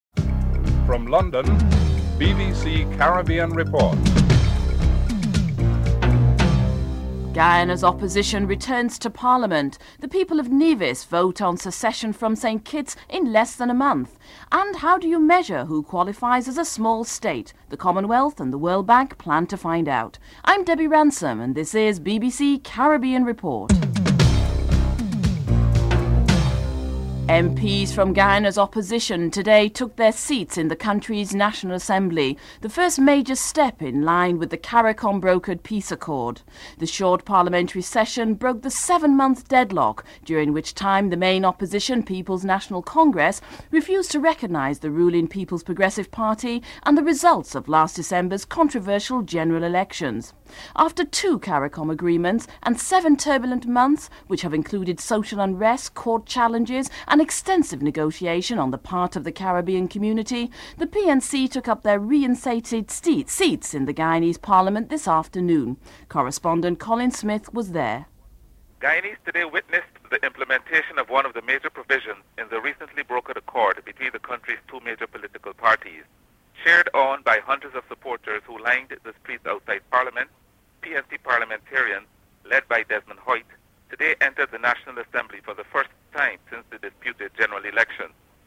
President Janet Jagan is interviewed.
Premier Vance Amory and Prime Minister Owen Arthur are interviewed.